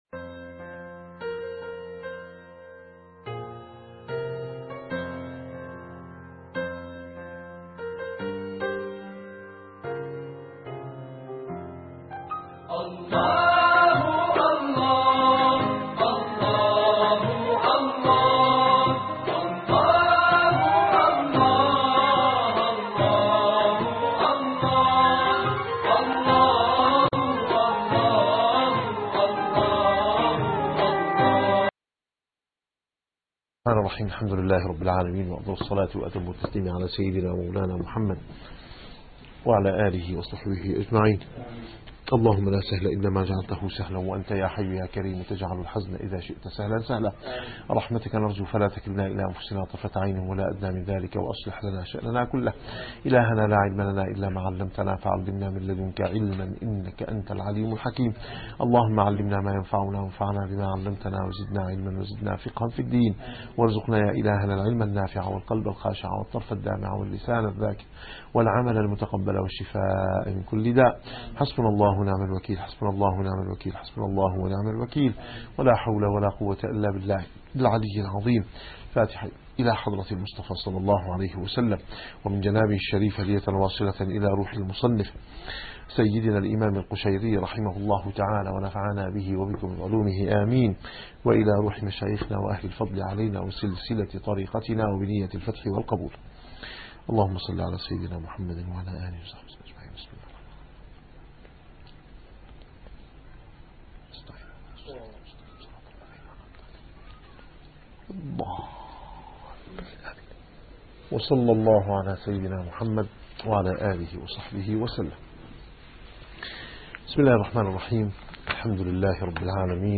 - الدروس العلمية - الرسالة القشيرية - الرسالة القشيرية / الدرس السابع والعشرون بعد المئة.